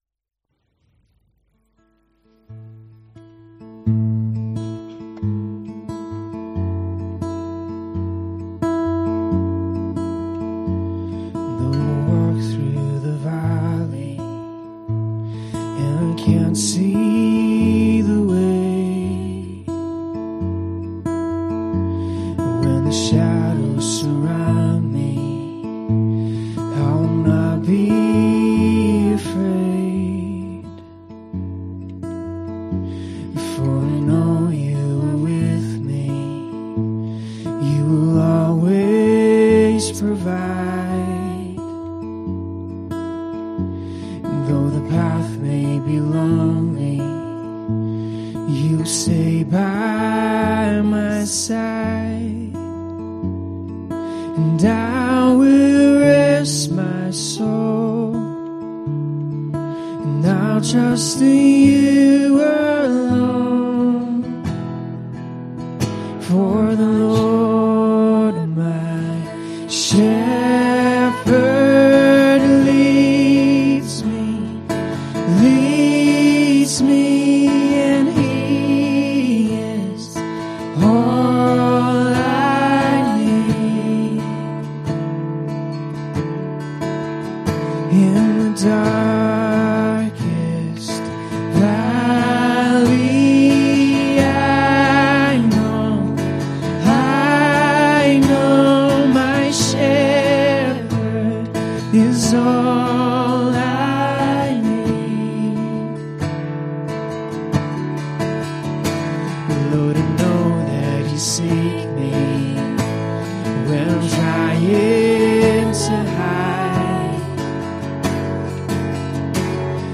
Ministry Song https
Service Type: pm